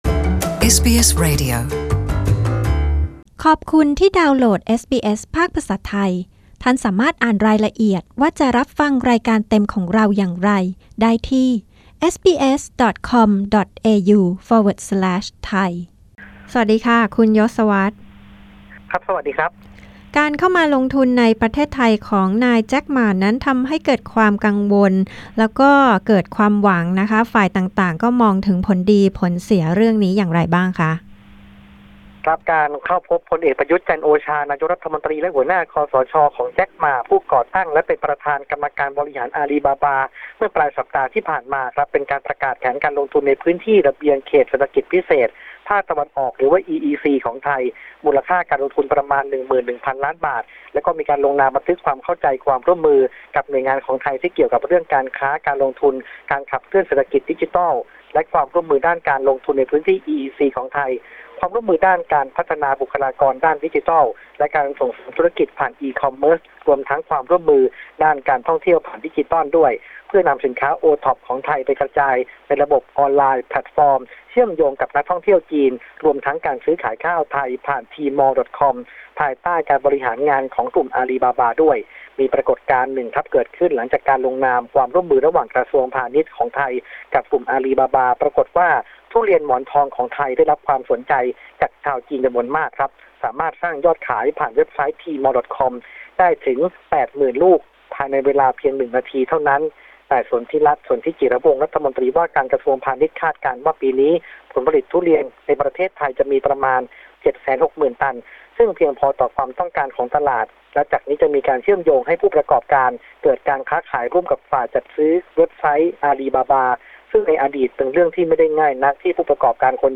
News: ฟังผู้สื่อข่าวพิเศษของเอสบีเอสประจำประเทศไทย รายงานความคืบหน้าเรื่องผลดีและผลเสียของการลงทุนโดยนายแจ็ค มา ในไทย เรื่องคดีทุจริตเงินทอนวัด และข้อเสนอของแกนนำพรรคเพื่อไทยให้ยกเลิกการเกณฑ์ทหาร